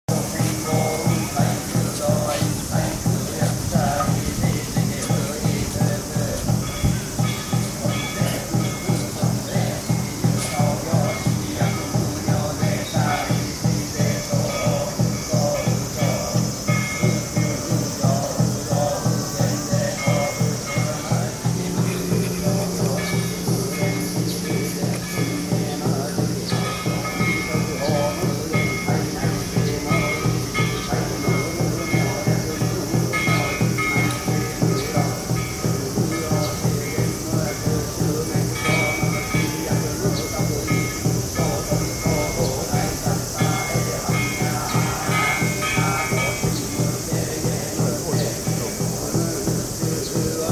recording of musicians playing in Hiroshima Peace Park, Japan, 2014.